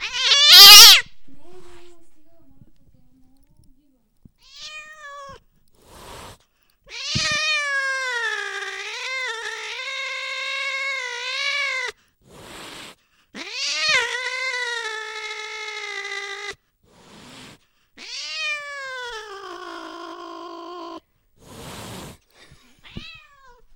دانلود صدای گربه عصبانی و وحشی از ساعد نیوز با لینک مستقیم و کیفیت بالا
جلوه های صوتی